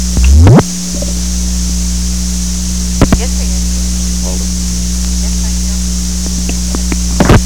Recording Device: White House Telephone
The White House Telephone taping system captured this recording, which is known as Conversation 017-017 of the White House Tapes.
The President talked with the White House operator.